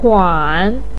tuan3.mp3